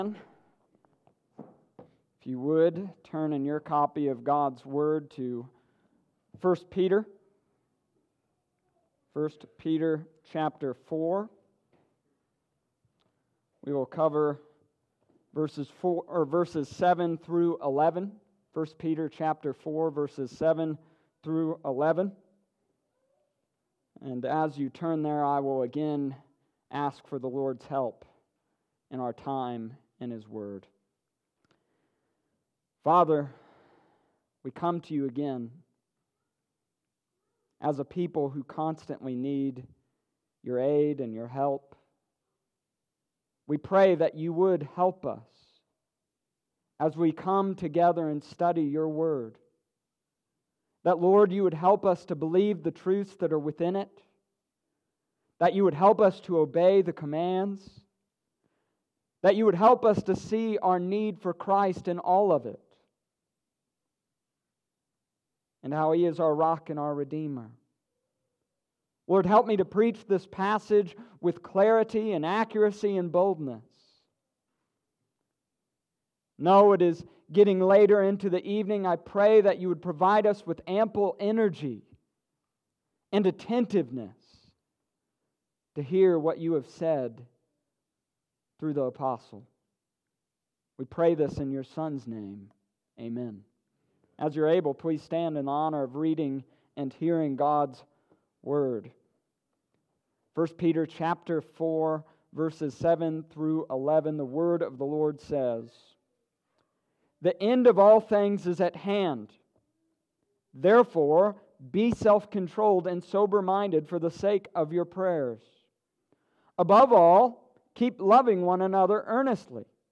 1 Peter chapter 4 verses 1-11 Sept 8th 2019 Sunday evening service